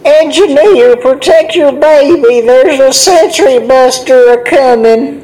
mvm_sentry_buster_alerts05.mp3